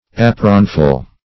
apronful - definition of apronful - synonyms, pronunciation, spelling from Free Dictionary Search Result for " apronful" : The Collaborative International Dictionary of English v.0.48: Apronful \A"pron*ful\, n.; pl.